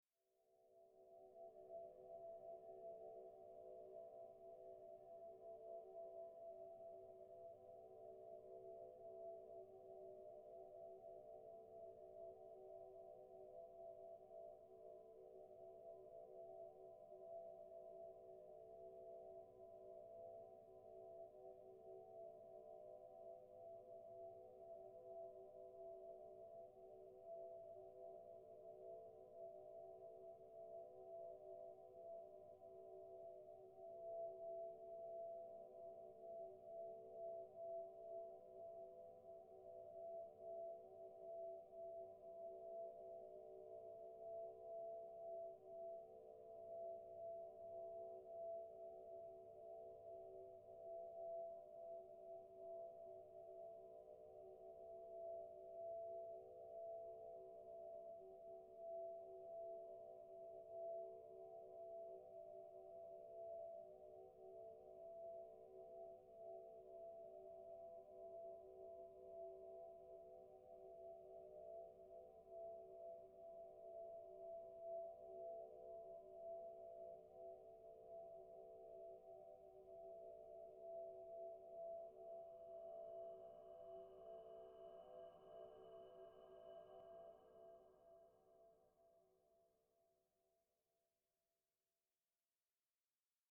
Фантастика: Космический ветер